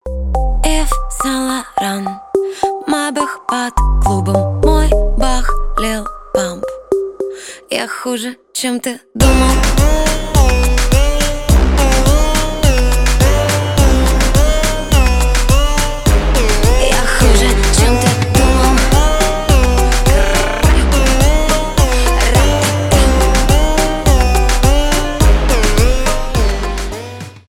trap , поп